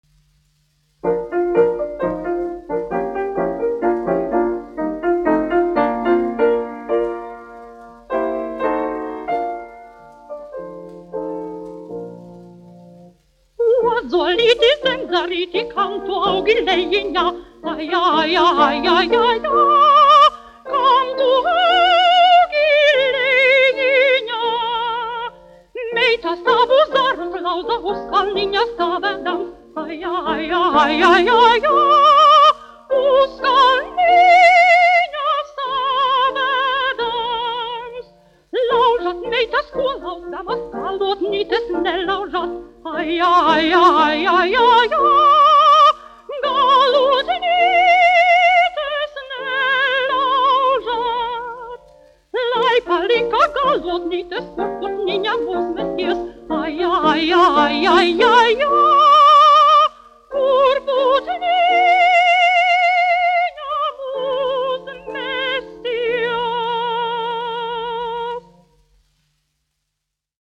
Ozolīti, zemzarīti : latviešu tautas dziesma
dziedātājs
instrumentālists
1 skpl. : analogs, 78 apgr/min, mono ; 25 cm
Latvijas vēsturiskie šellaka skaņuplašu ieraksti (Kolekcija)